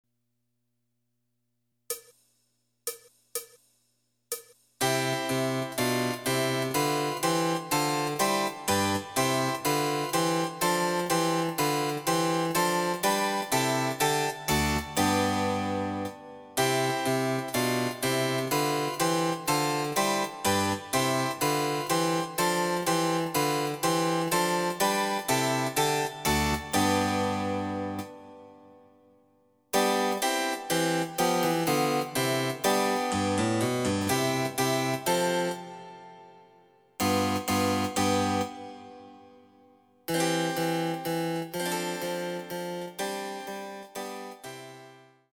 その他の伴奏
Electoric Harpsichord